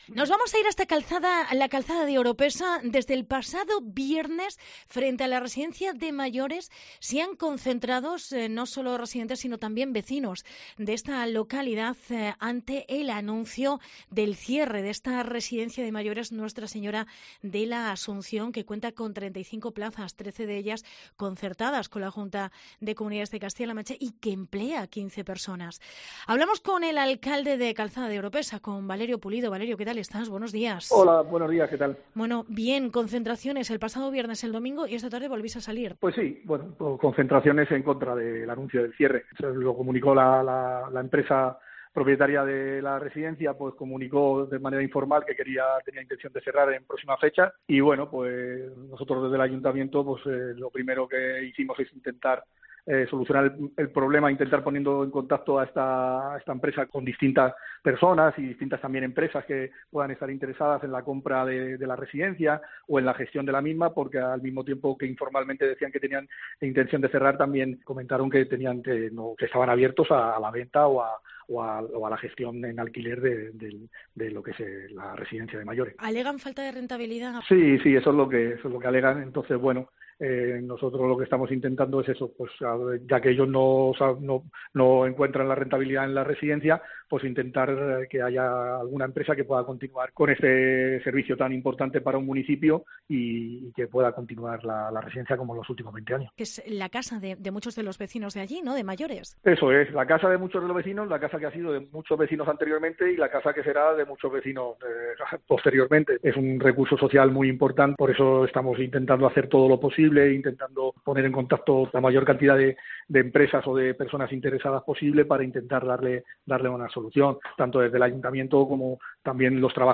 Entrevista Valerio Pulido, alcalde de La Calzada de Oropesa